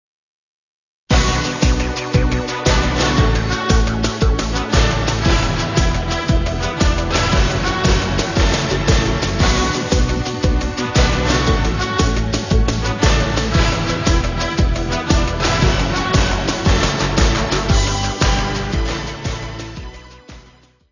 Gravação em stereo
Music Category/Genre:  Pop / Rock